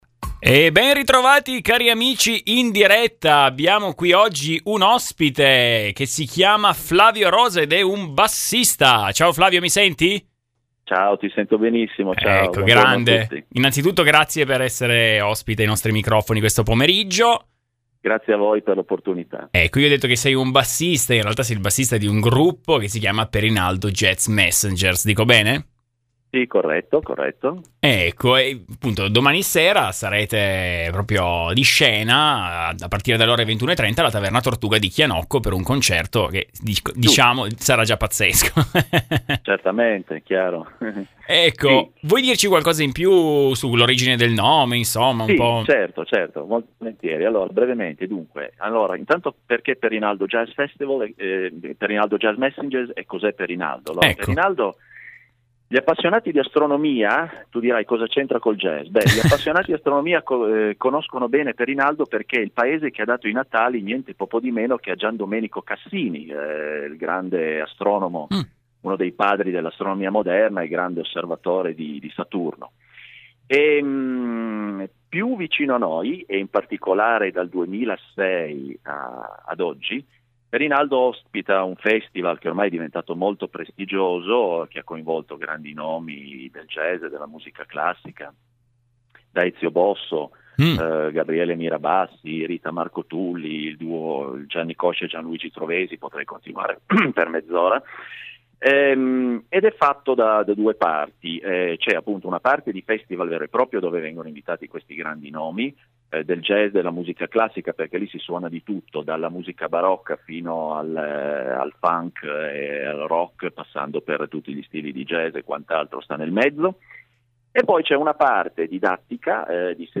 Oggi pomeriggio abbiamo avuto il piacere di ospitare ai nostri microfoni il bassista